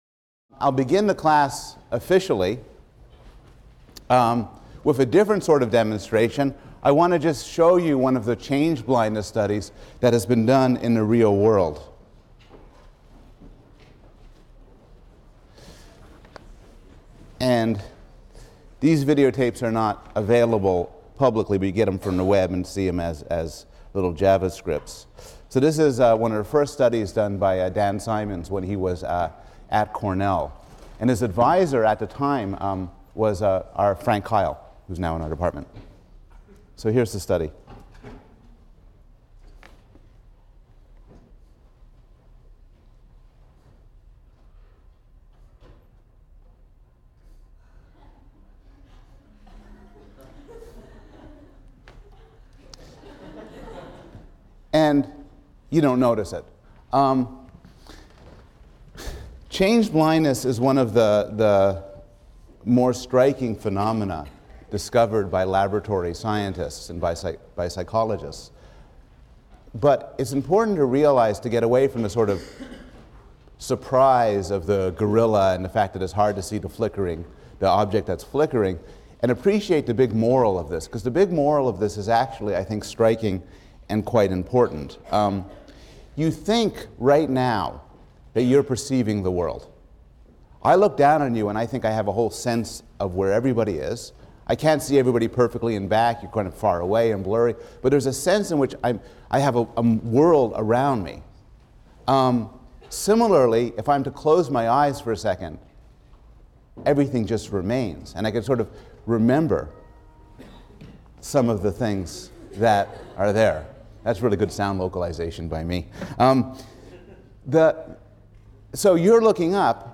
PSYC 110 - Lecture 8 - Conscious of the Present; Conscious of the Past: Vision and Memory (cont.) | Open Yale Courses